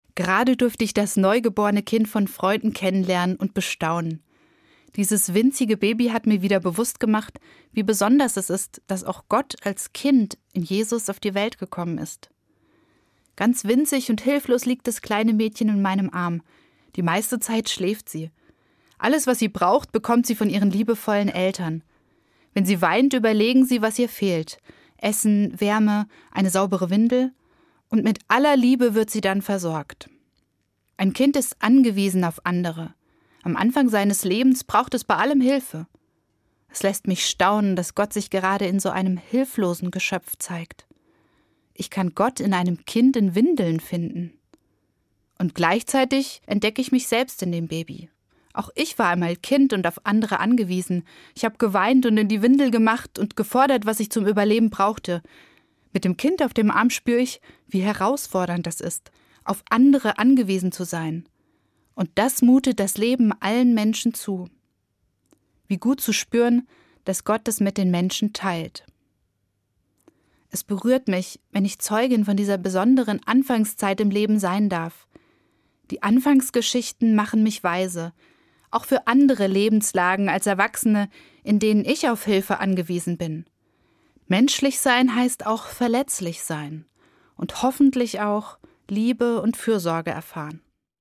Evangelische Pfarrerin, Gießen